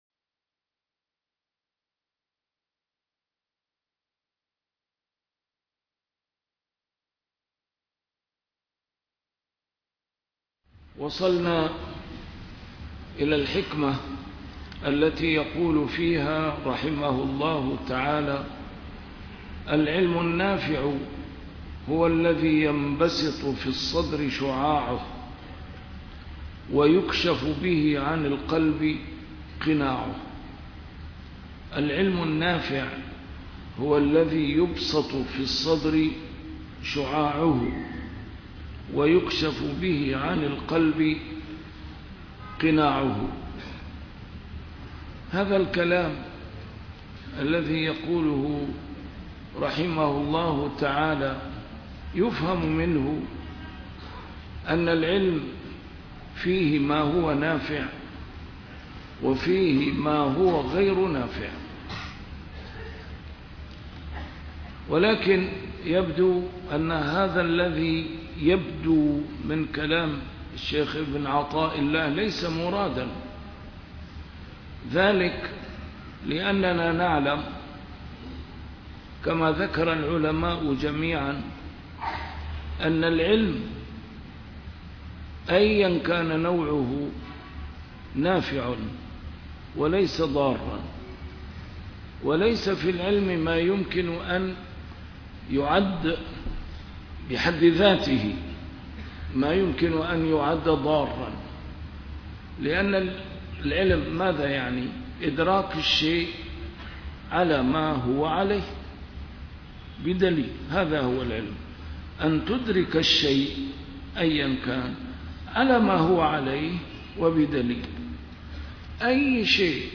الدرس رقم 253 شرح الحكمة رقم 230